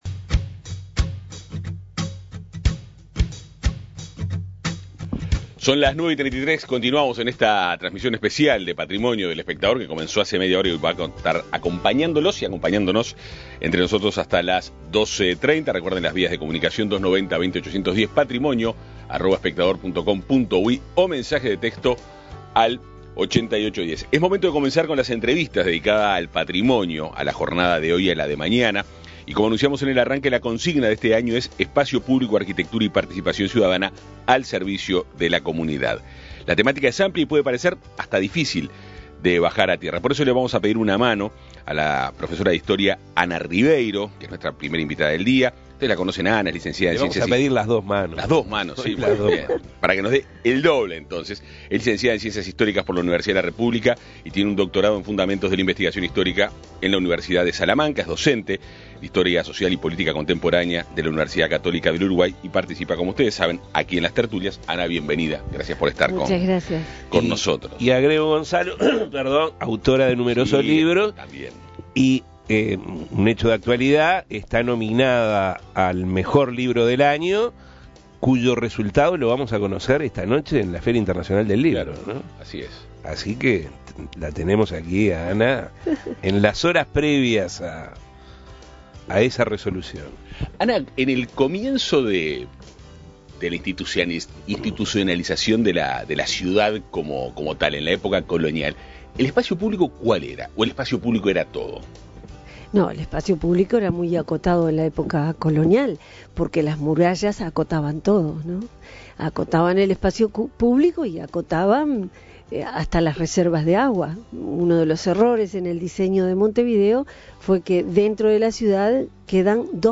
visitó los estudios de El Espectador para contextualizar el sentido histórico del espacio público ante la celebración de una nueva edición del Día del Patrimonio.
entrevista